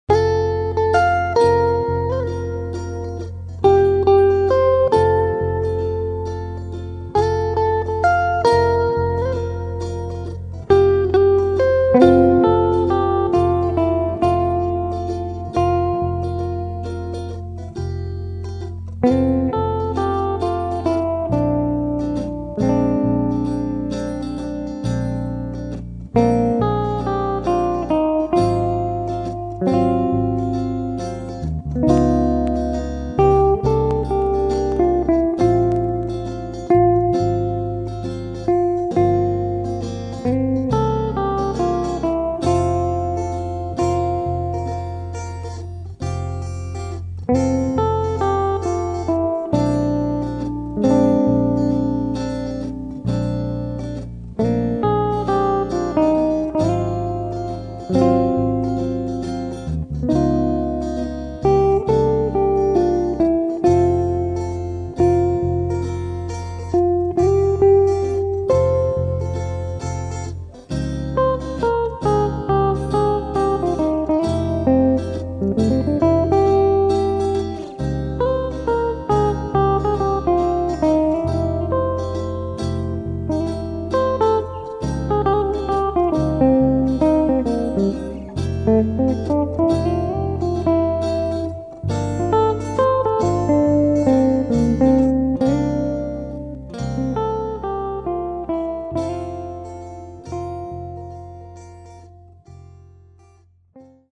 Lento a duine.